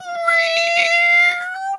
Animal_Impersonations
cat_2_meow_long_03.wav